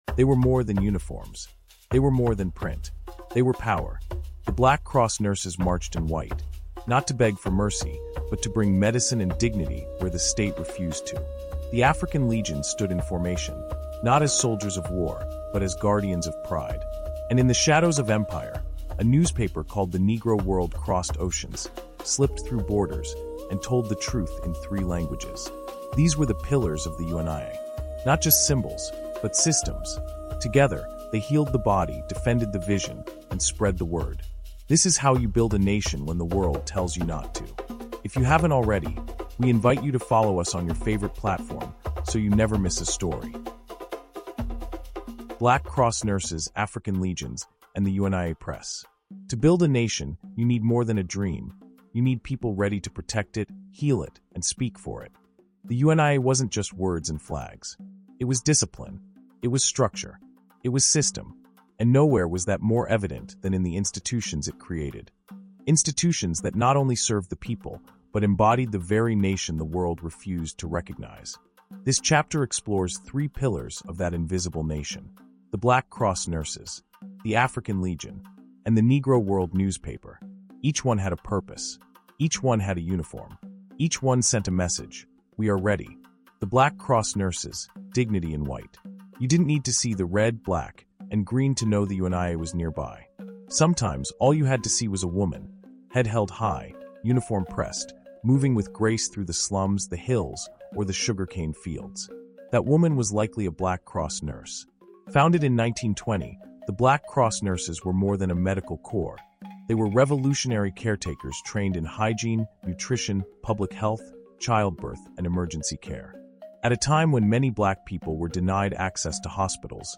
UNIA Hidden History of Black Cross Nurses That Changed America | Audiobook